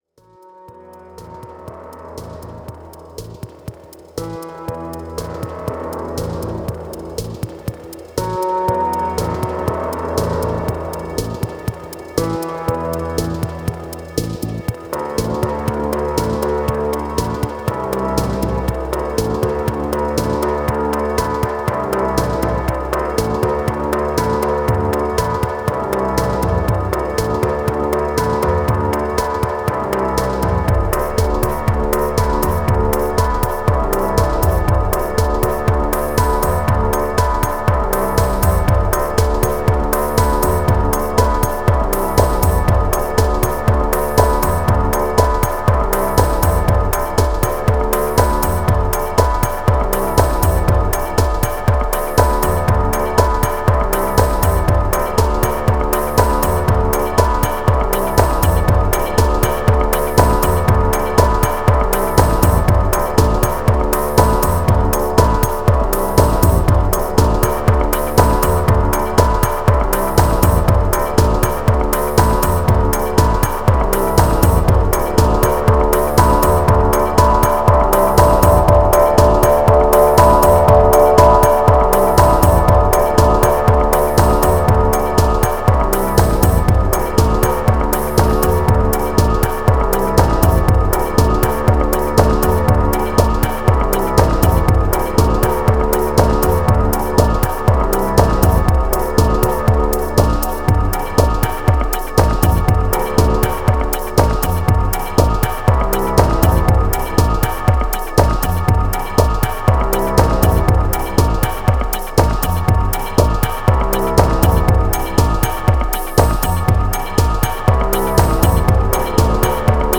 Dub Dark Moods Resume Clone Virtual Bass Hopeless